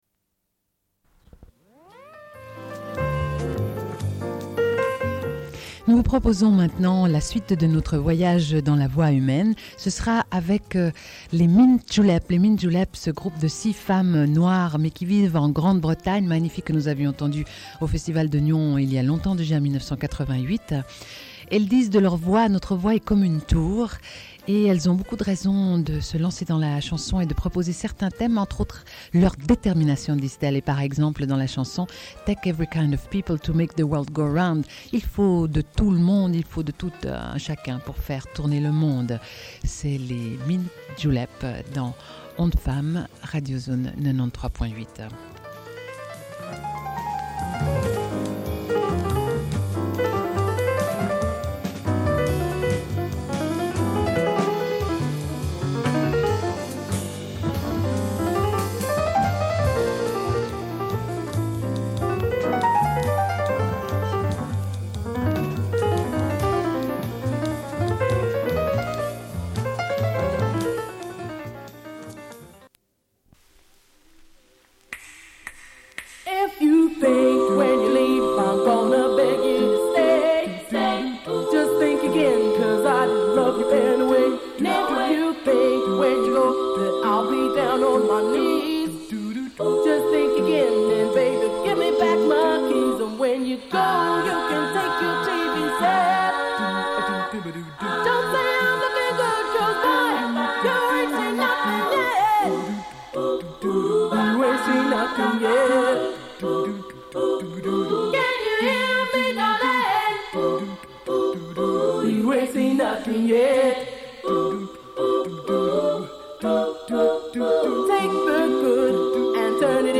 Suite de l'émission : chronique « Voix humaine », musique ou lecture. Cette semaine, émission musicale.
Une cassette audio, face B